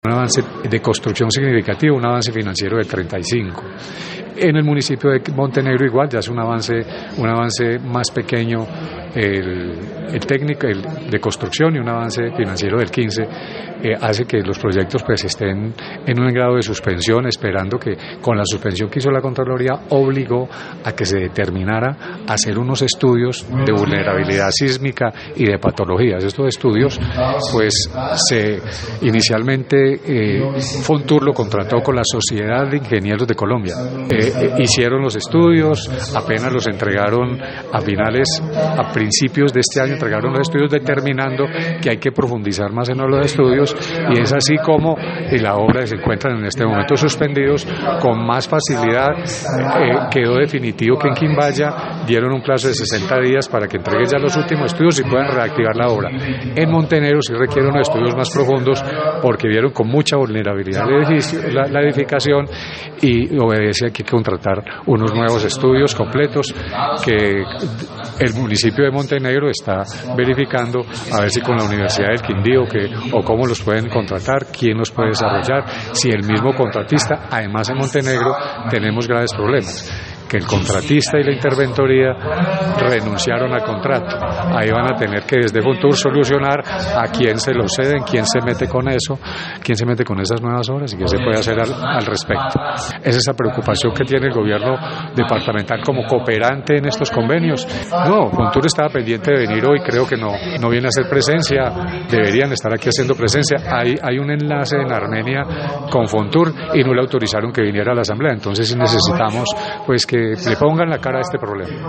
Rubén Dario Castillo, secretario de Infraestructura
En el marco de un debate en la asamblea departamental sobre la situación de dichas obras, el secretario de infraestructura del Quindío Rubén Dario Castillo realizó un recuento de los procesos y su actualidad.